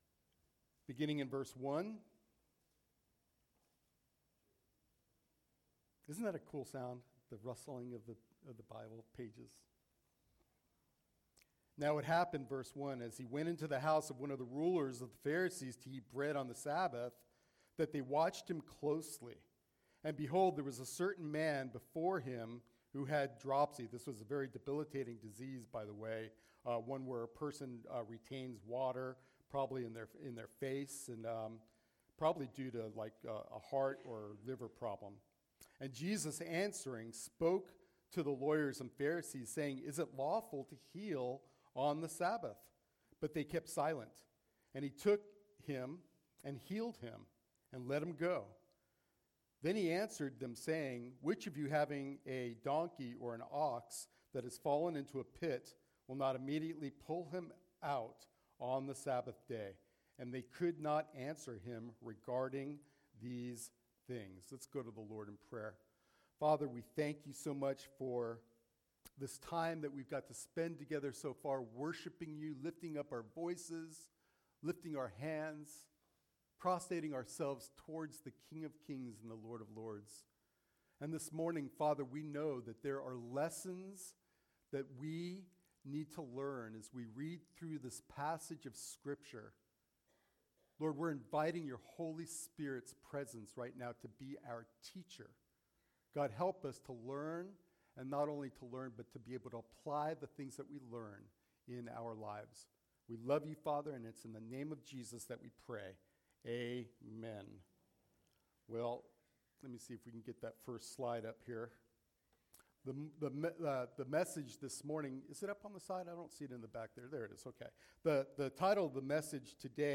sermon-9-4-22.mp3